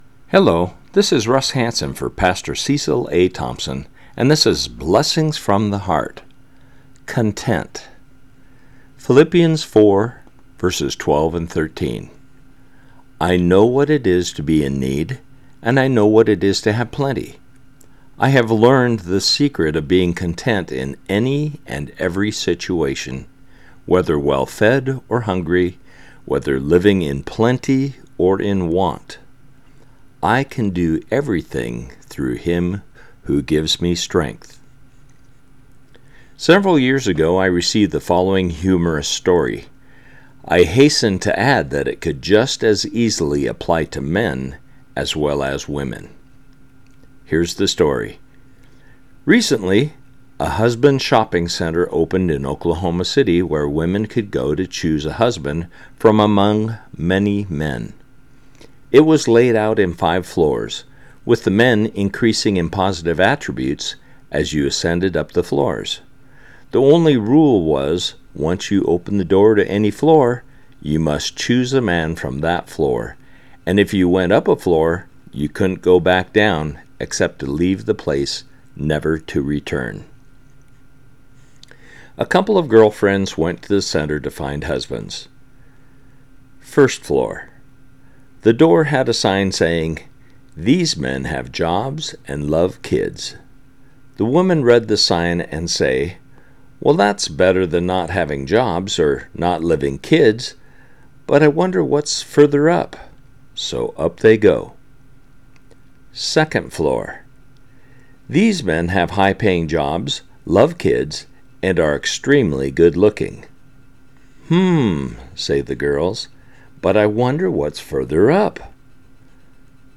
Philippians 4:12-13 – Devotional